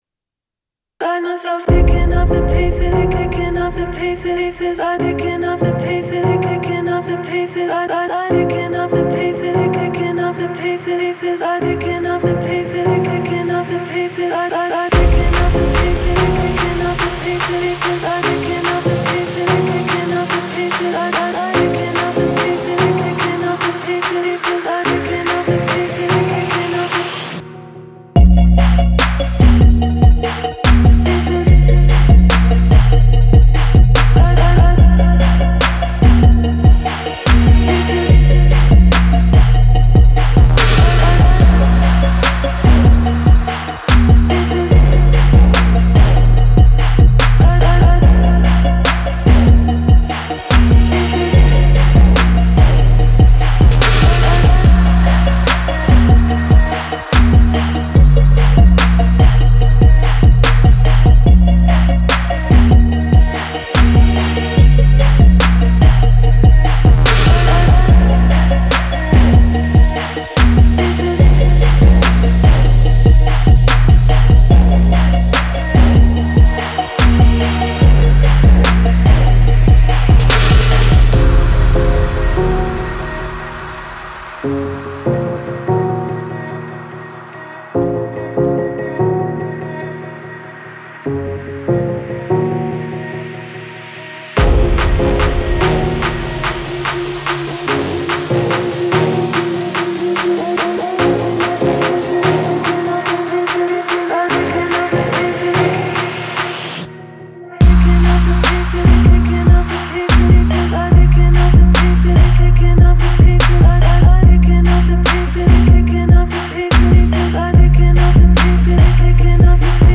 Trap.